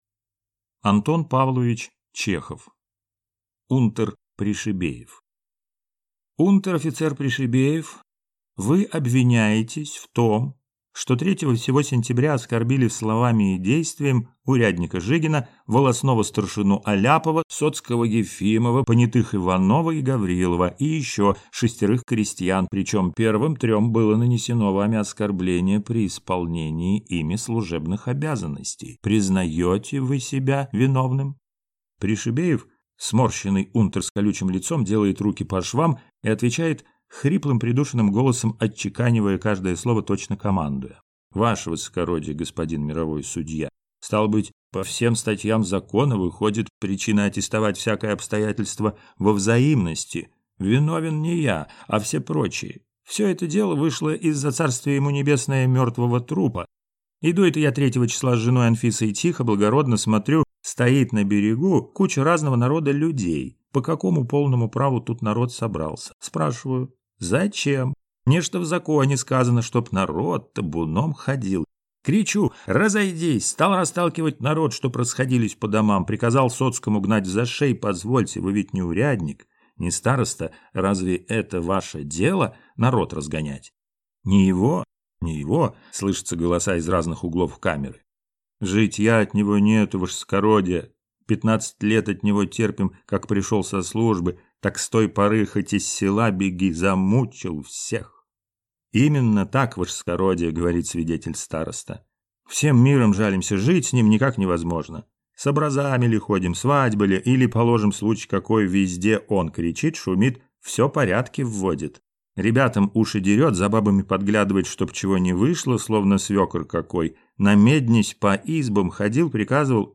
Аудиокнига Унтер Пришибеев | Библиотека аудиокниг